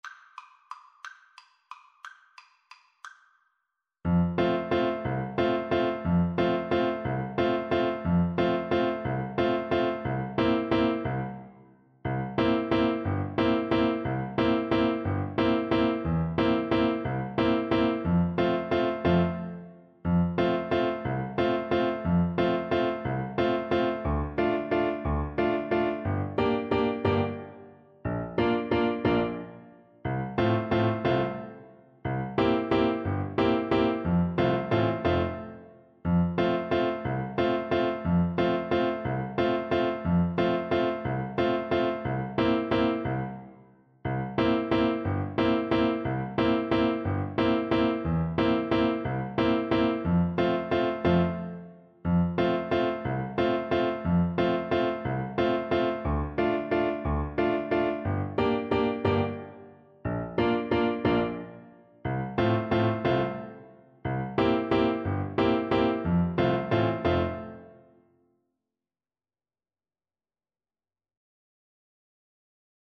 3/4 (View more 3/4 Music)
One in a bar .=c.60